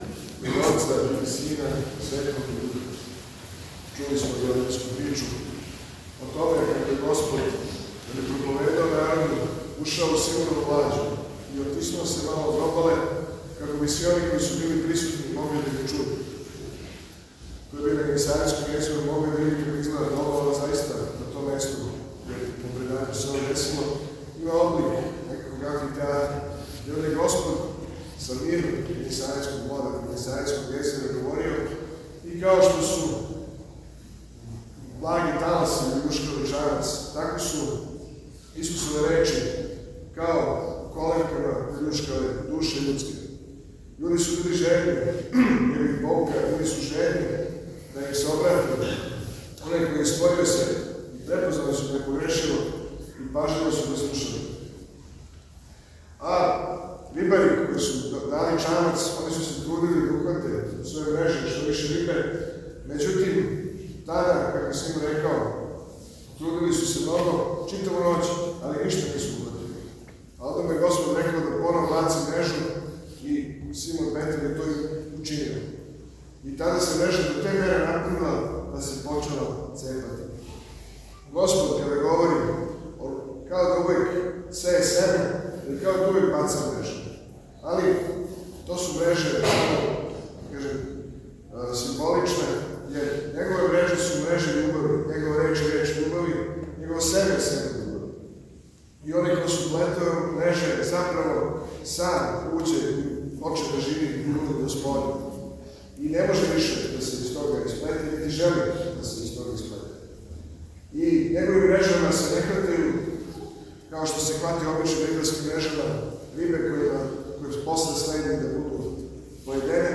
Серијал „Са амвона“, у оквиру којег доносимо записе беседâ отачаствених архијереја, презвитерâ, ђаконâ, катихетâ и теологâ, портал „Кинонија“ реализује у сарадњи са редакцијама информативних служби епархијâ Српске Православне Цркве, као и у сарадњи са Телевизијом „Храм“ и епархијским радио-станицама.